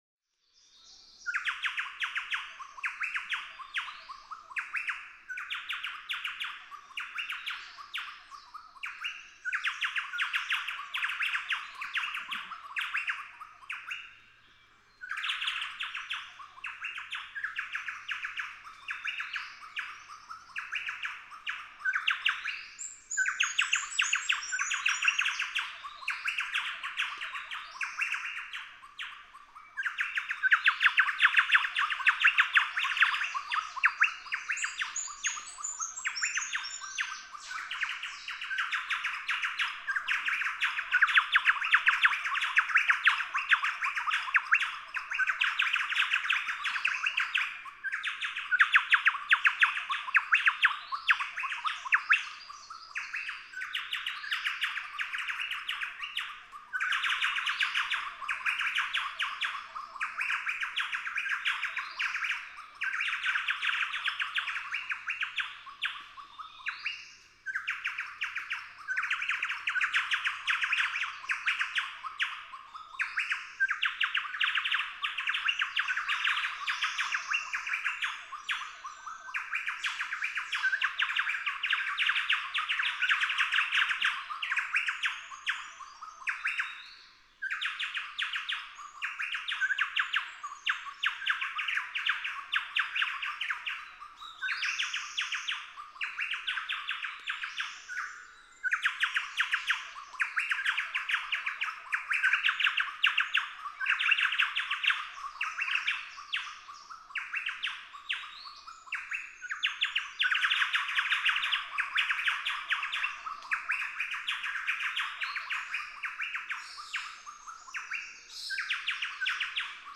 Chowchilla
Not chinchilla, but chowchilla, with one of the finest sounds of the wet, tropical rainforests of Australia.
A group. Unbeknownst to me, a friend just down the road had played chowchilla songs to these birds that then came my way. Wow, all members of the flock now seem to be fully engaged in proclaiming who they are and what real estate they own!
Near the Cathedral Fig Tree, Atherton Tablelands, Queensland.
724_Chowchilla.mp3